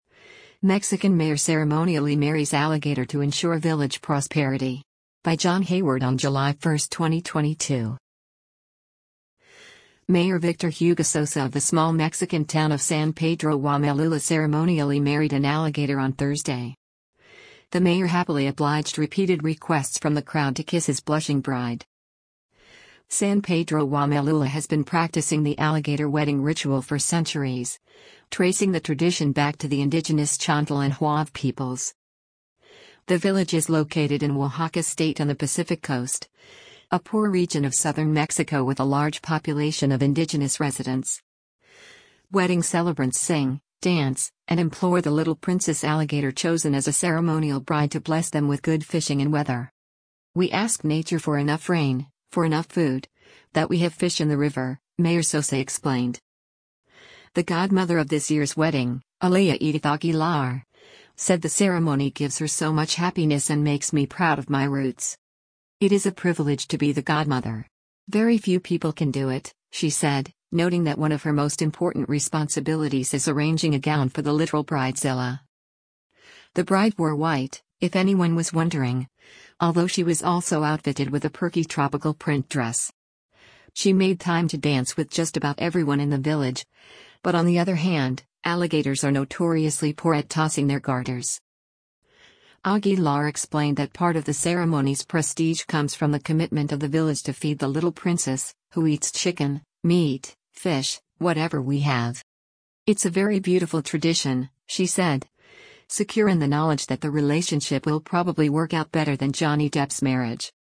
The mayor happily obliged repeated requests from the crowd to kiss his blushing bride.
Wedding celebrants sing, dance, and implore the “little princess” alligator chosen as a ceremonial bride to bless them with good fishing and weather.